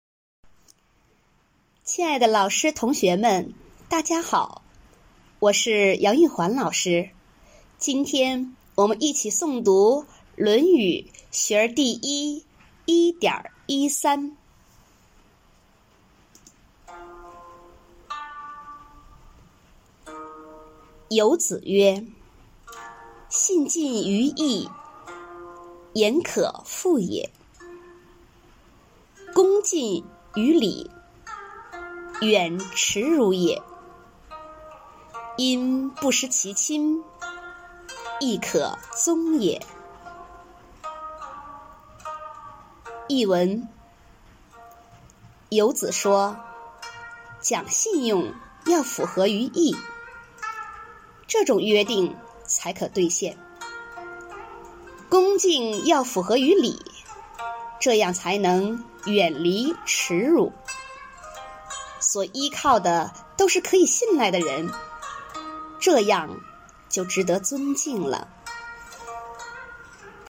每日一诵0304.mp3